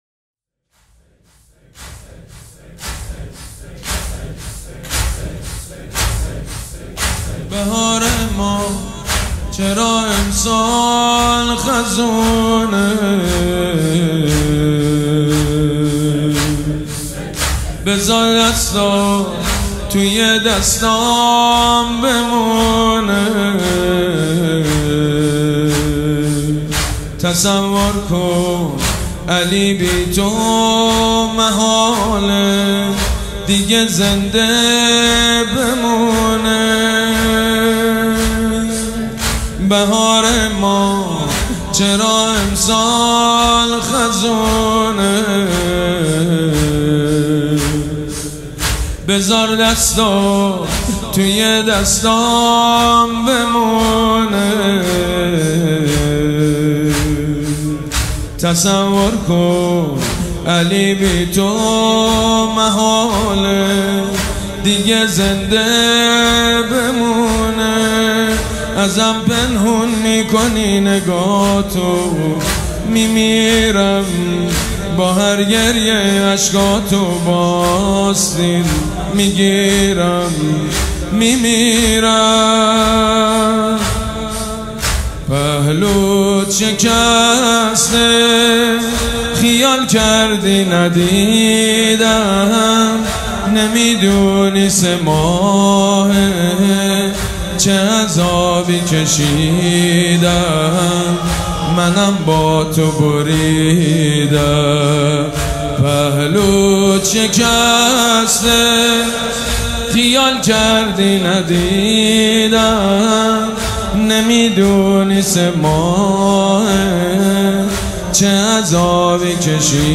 اللیالی الفاطمیة 1441 هـ | هیأت ریحانة الحسین - طهران